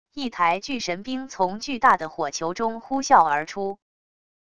一台巨神兵从巨大的火球中呼啸而出wav音频